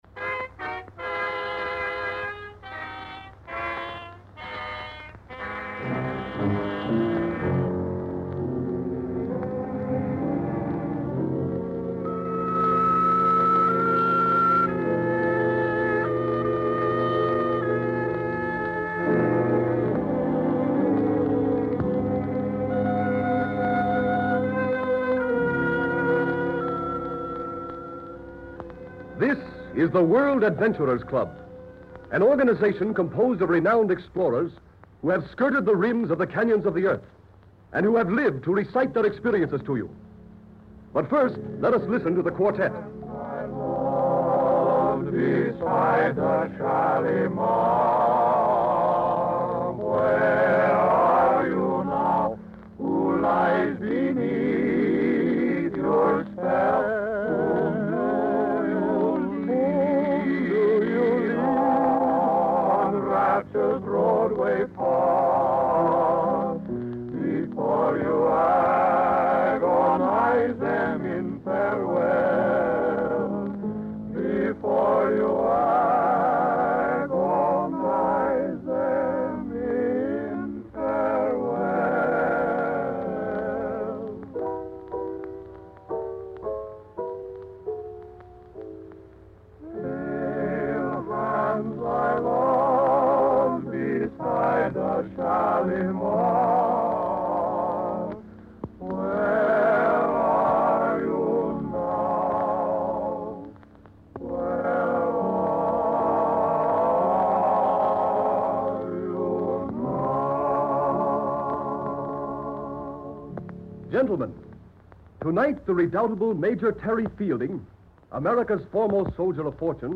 The World Adventurer's Club, a radio series from the golden age of broadcasting, captured the essence of such exotic locales in its episode "India, The Mystery Land". This particular episode, like the others in the series, transported listeners to far-off destinations, offering them a taste of adventure from the comfort of their homes.